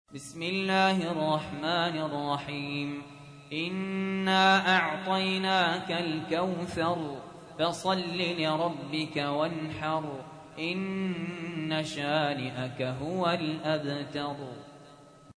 تحميل : 108. سورة الكوثر / القارئ سهل ياسين / القرآن الكريم / موقع يا حسين